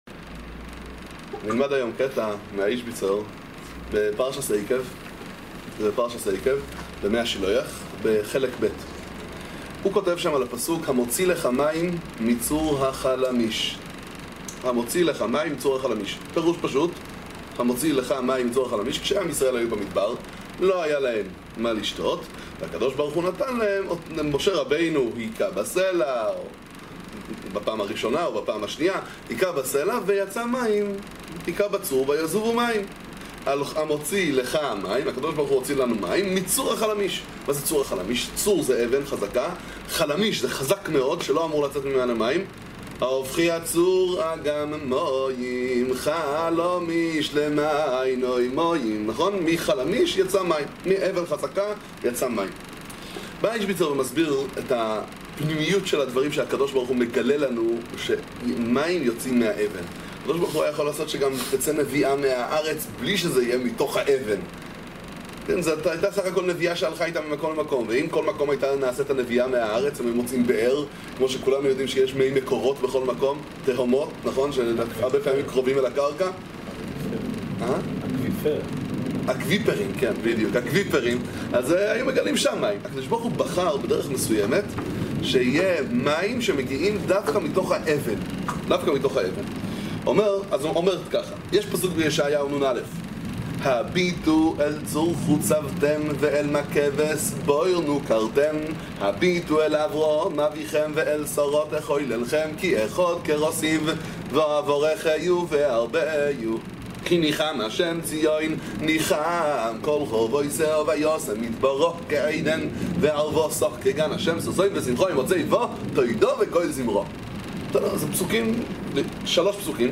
שיעור תורה בספר מי השילוח איז'ביצא, ובספרי הכהן מלובלין